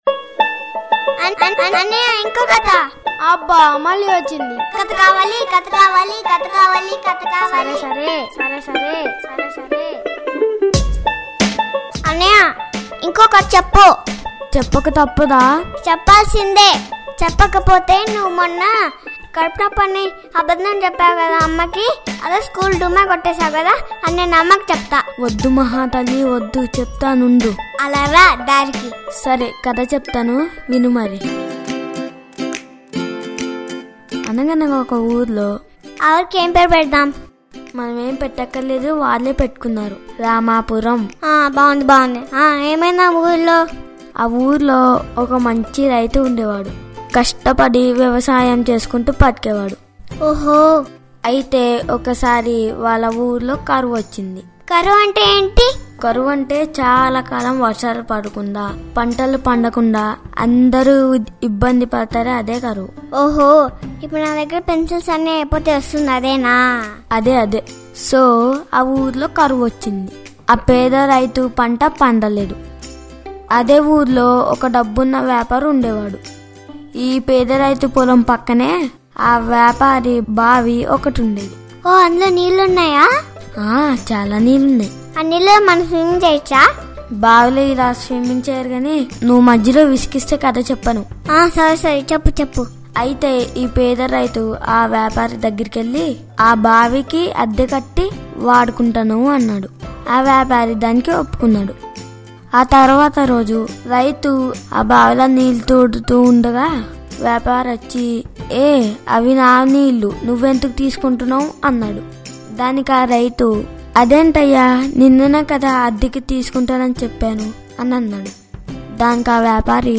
play_arrow Download MP3 Go Mobile రైతు భావి - పిల్లలు కథ | Raithu Baavi - The Farmer And Well | Kids Story | Telugu Podcast పిల్లల కథలు - పిల్లలతో l Pillala Kathalu - Pillalatho!